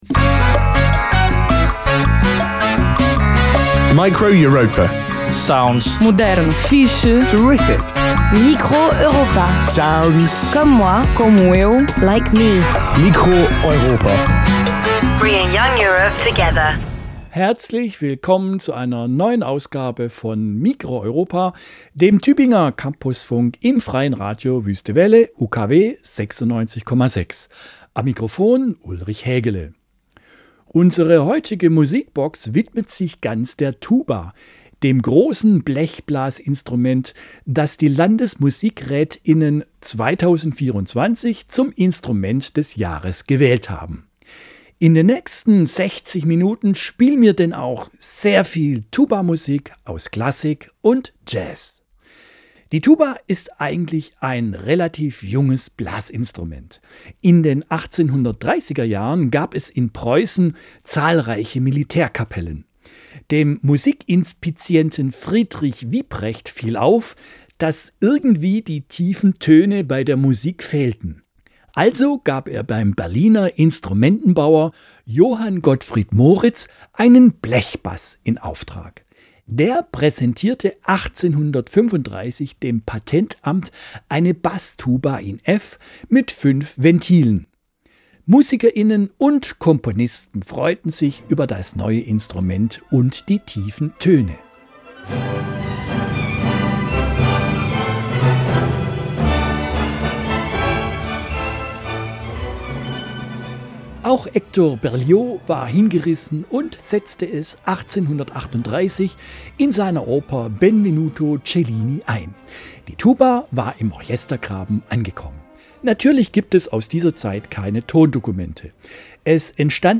Unsere heutige Musikbox widmet sich ganz der Tuba, dem großen Blechblasinstrument, das die Landesmusikrät*innen 2024 zum Instrument des Jahres gewählt haben. In den nächsten 60 Minuten spielen wir denn auch viel Tuba-Musik aus Klassik und Jazz.
Form: Live-Aufzeichnung, geschnitten